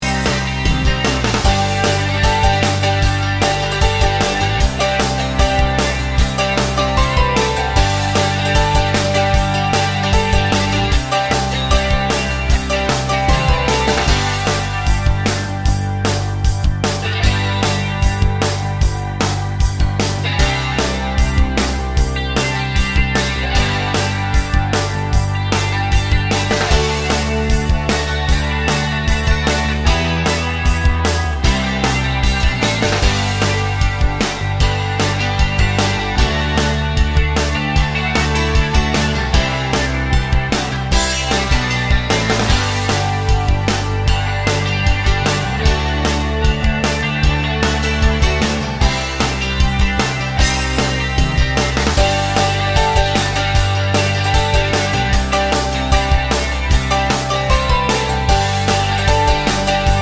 no Backing Vocals Rock 3:17 Buy £1.50